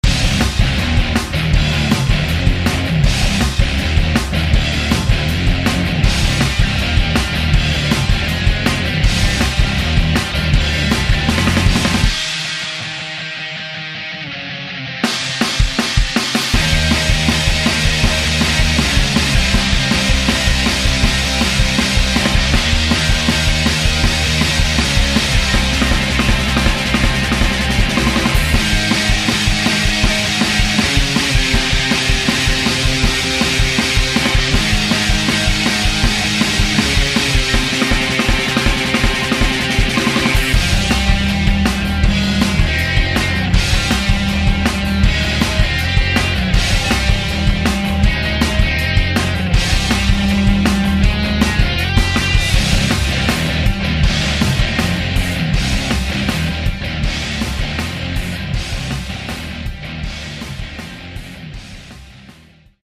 2 . rock /